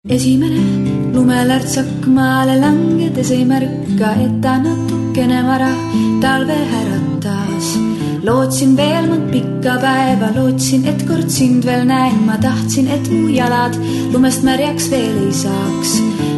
- No näed siis C I VI MP3